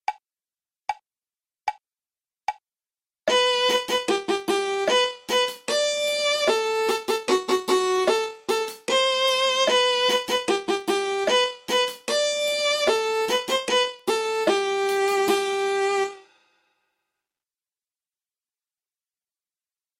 "Skip to My Lou" melody track